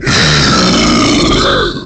File:Dogadon's Godzilla Snarl.oga
Dogadon sound effect from Donkey Kong 64
Dogadon's_Godzilla_Snarl.oga.mp3